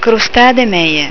(pronuncia)   farina bianca, zucchero, uova, lievito per dolci